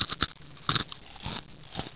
00749_Sound_crunch.mp3